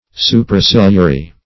Supraciliary \Su`pra*cil"i*a*ry\